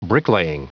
Prononciation du mot bricklaying en anglais (fichier audio)
Prononciation du mot : bricklaying